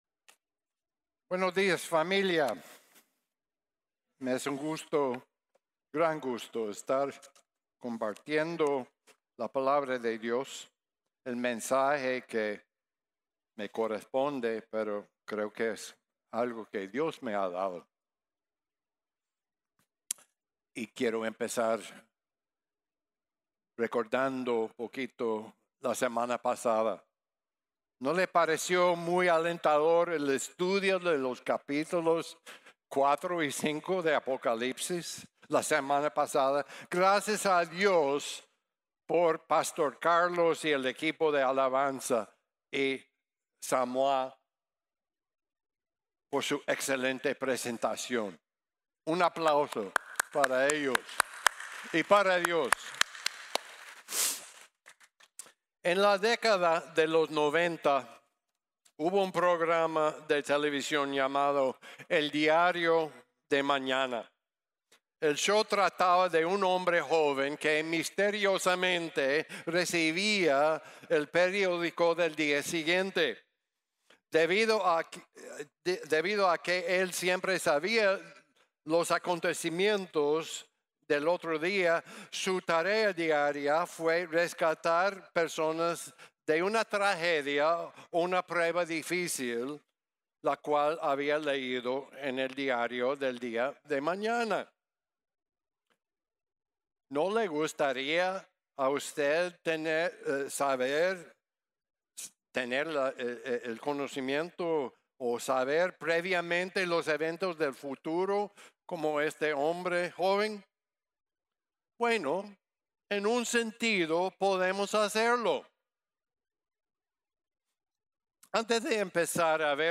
Las sentencias de los Sellos | Sermon | Grace Bible Church